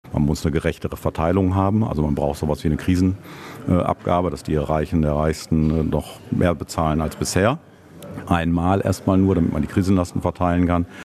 Um all den Problemen gerecht werden zu können, brauche der Staat mehr Einnahmen, sagte Post im Radio-Hagen-Gespräch: